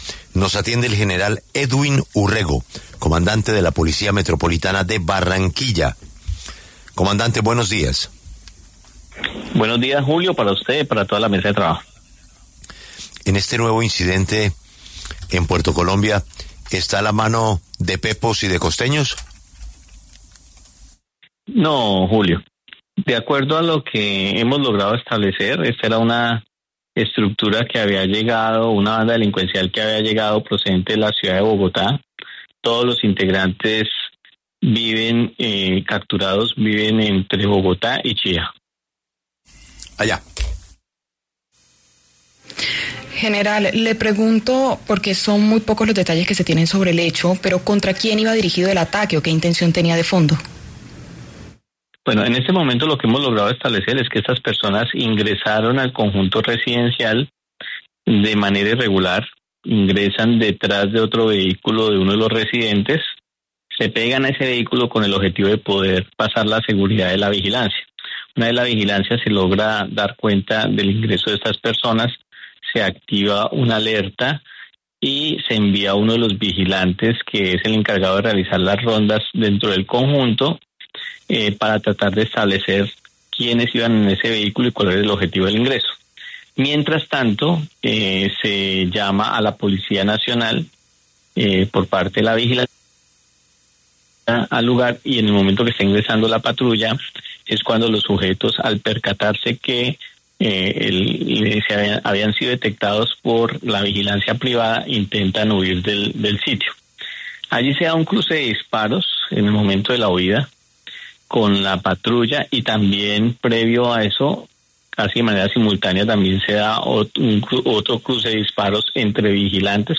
En conversación con La W, el brigadier general Edwin Urrego, comandante de la Policía Metropolitana de Barranquilla, se refirió al hecho criminal.